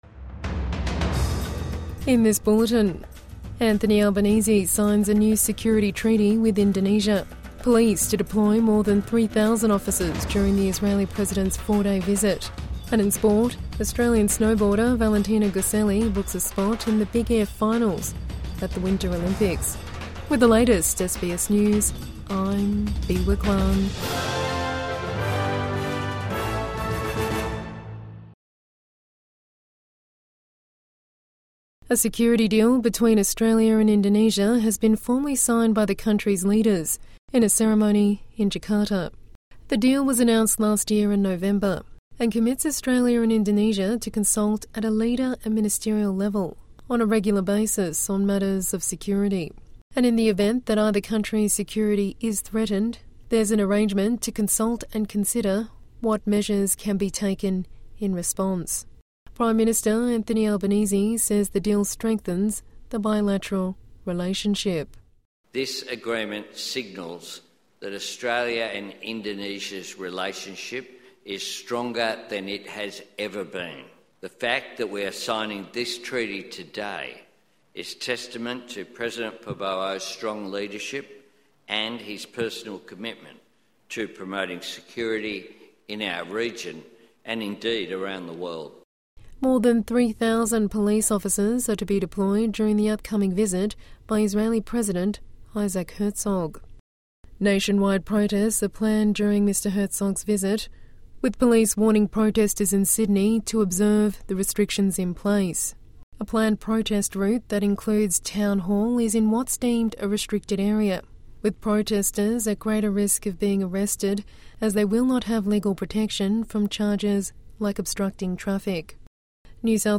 New security pact with Indonesia signed in Jakarta | Evening News Bulletin 6 February 2026